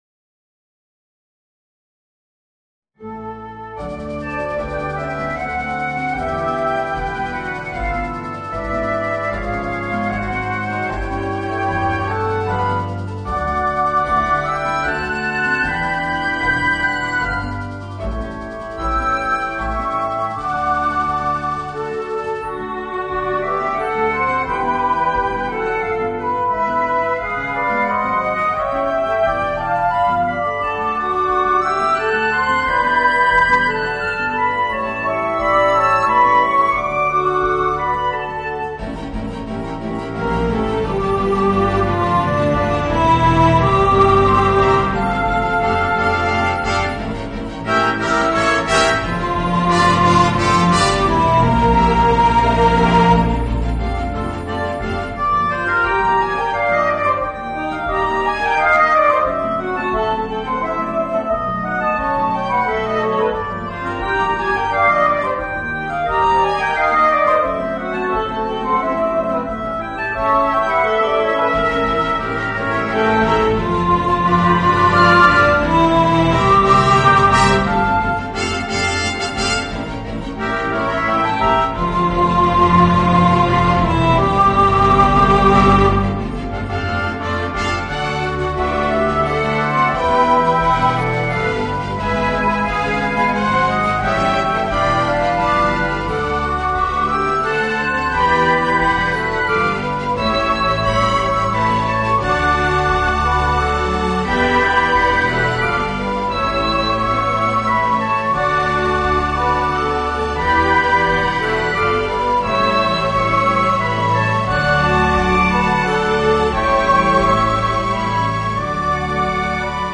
Voicing: Mezzo Soprano and Orchestra